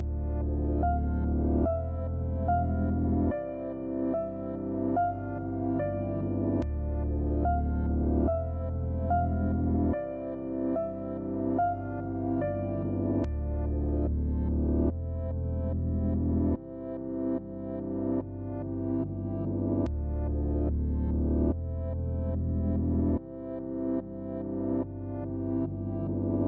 描述：和钢琴n钢琴...反转
Tag: 145 bpm Trap Loops Piano Loops 4.46 MB wav Key : B